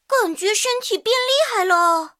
T54强化语音.OGG